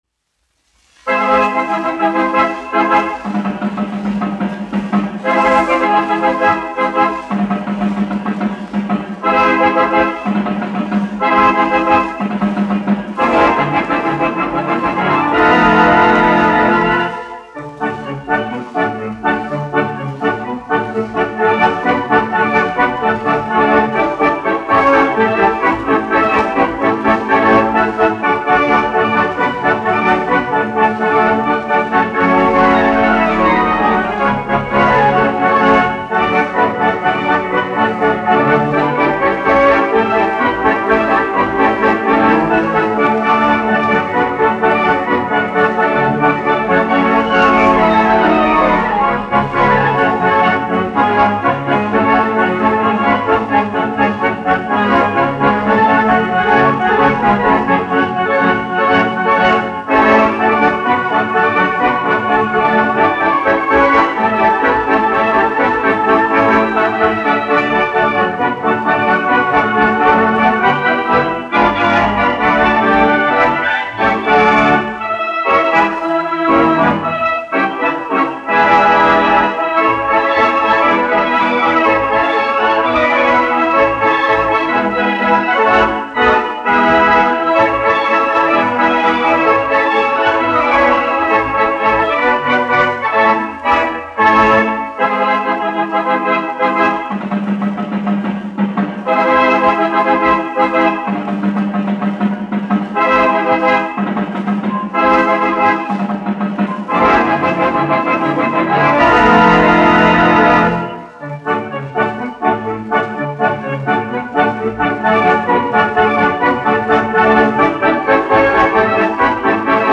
1 skpl. : analogs, 78 apgr/min, mono ; 25 cm
Populārā instrumentālā mūzika
Kino ērģeles
Skaņuplate